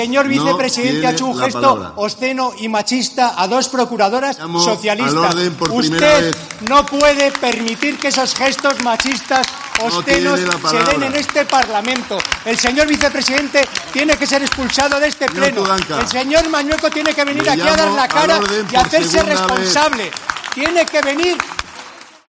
Luis Tudanca (PSOE) pide expulsar del pleno de las Cortes al vicepresidente de Castilla y León